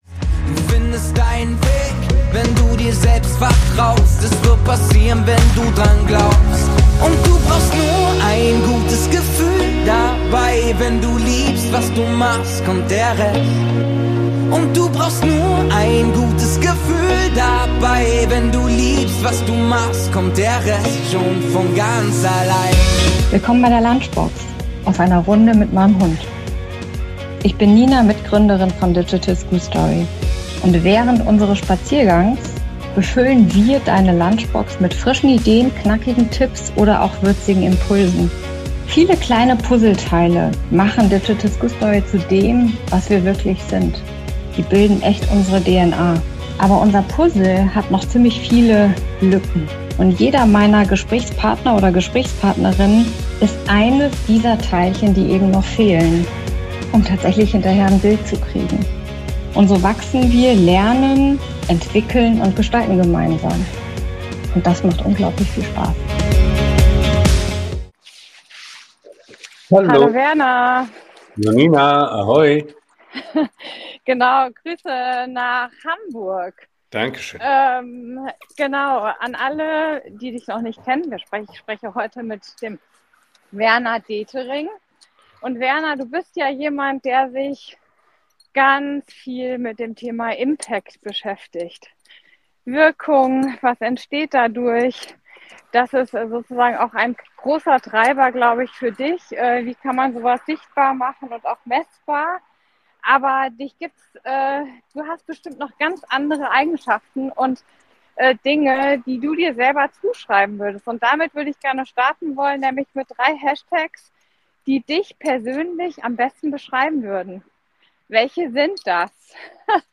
Während unseres Spaziergangs haben wir Deine Lunchbox befüllt mit Themen rund um Wirkung, Messung von Wirkung und die IOOI-Logik. Warum es mehr Wirkung braucht und was Wirkung überhaupt ist. Und zugleich erfahrt Ihr auch, was wir bei DigitalSchoolStory als Impact definieren und wie wir unsere Wirkung messbar machen.